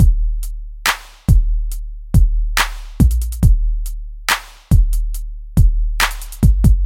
鼓乐队练习120BPM
描述：自定义鼓循环。3个Kontakt的工厂声音实例。低音鼓，汤姆鼓，小鼓。
Tag: 120 bpm Cinematic Loops Drum Loops 1.35 MB wav Key : Unknown